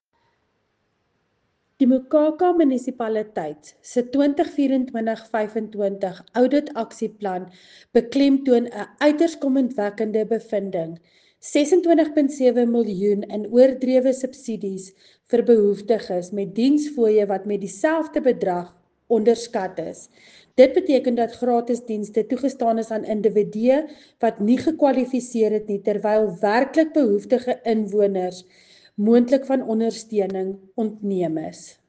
Afrikaans soundbite by Cllr Linda Louwrens